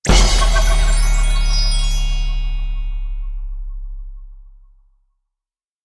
Звуки магии
Звук черной магии с превращением